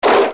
cartoon6.mp3